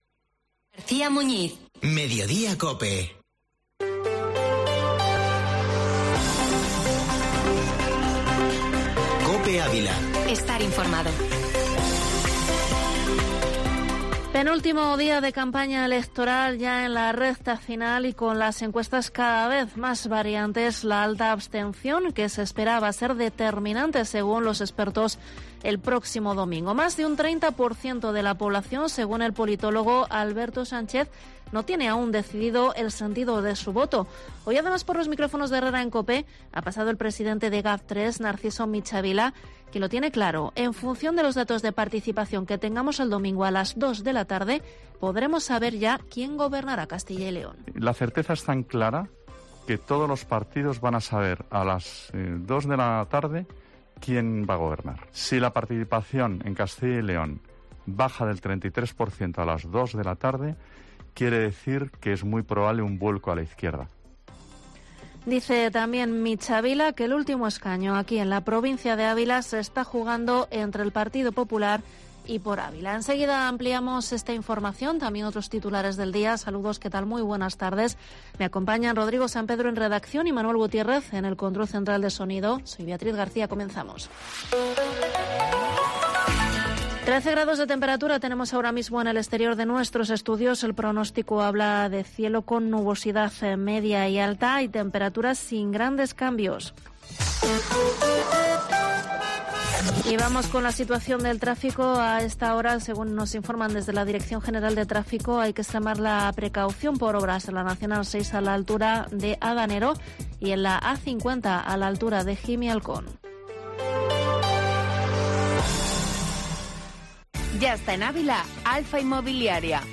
Informativo Mediodía COPE en Ávila 10/2/22